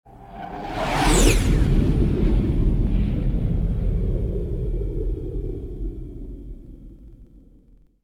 ExitSector.wav